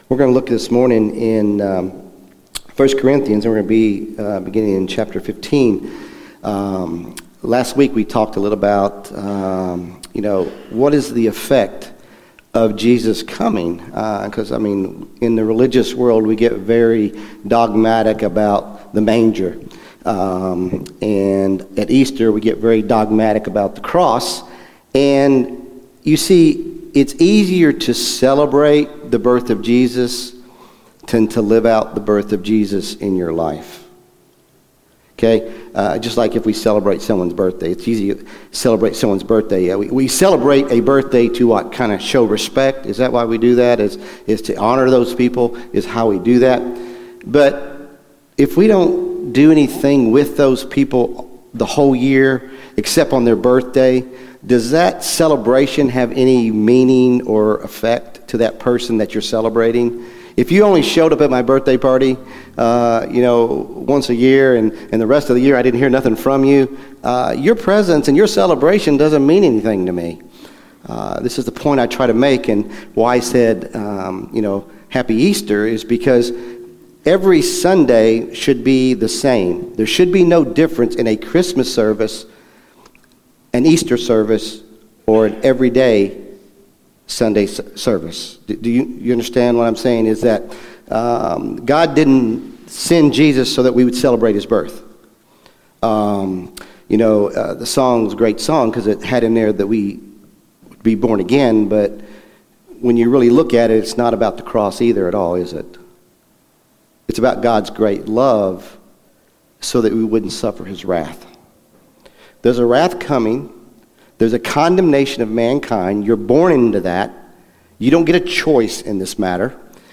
Sermon December 22, 2024 | South Elkhorn Baptist Church